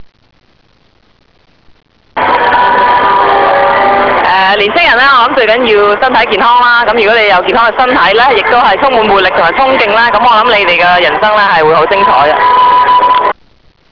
由青少年暑期活動員會及傑出青年協會合辦的「飛躍人生」講座經已於十一月二十日完滿結束。
當晚香港大球場體育大樓的賽馬會演講廳座無虛設，參加者多數是青少年。